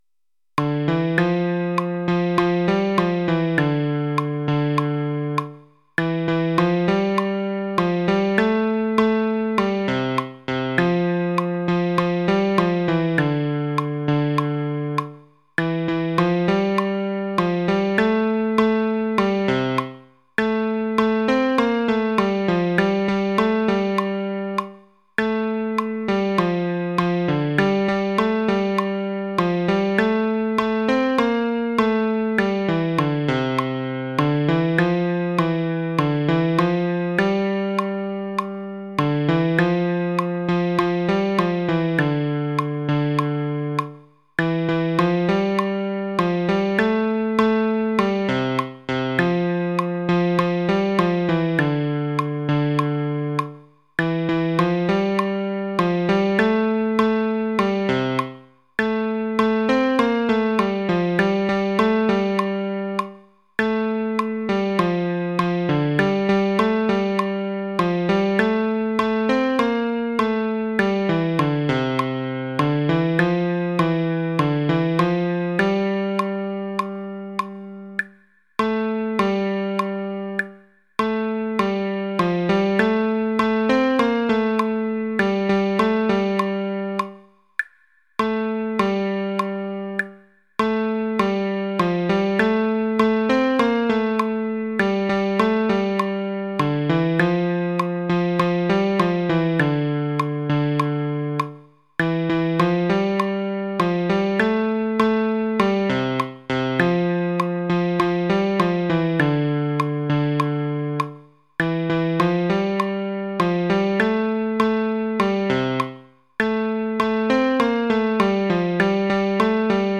Adjuntos: partitura, acordes y mp3, en RE menor.